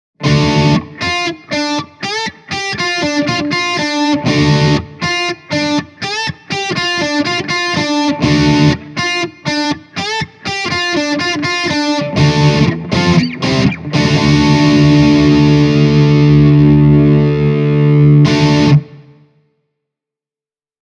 Rumblessa löytyy se sama lämpö ja avoimuus kuin monissa avoimissa kaapeissa/komboissa, mutta sen erikoisen rakenteen ansiosta äänen suuntavuus on huomattavasti parannettu.
Eminence-kaiuttimella varustettu blondi-Rumble soi täyteläisellä ja kermaisella äänellä: